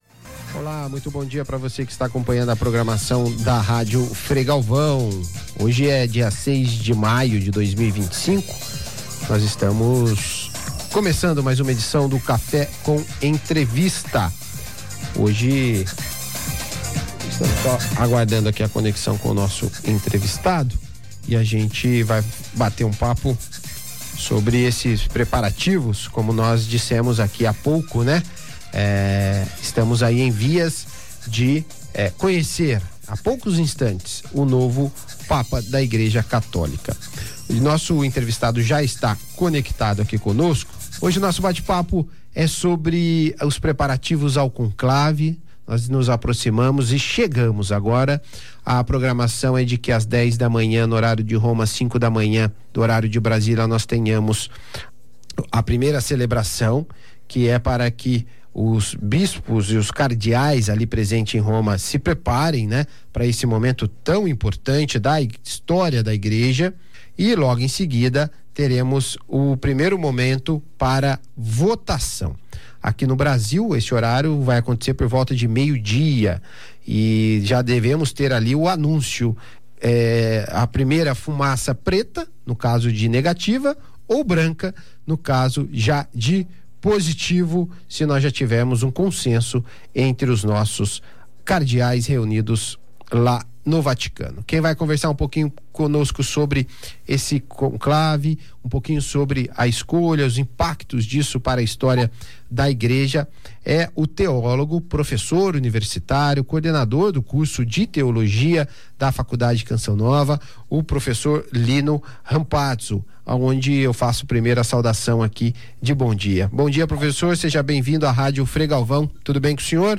do Café com Entrevista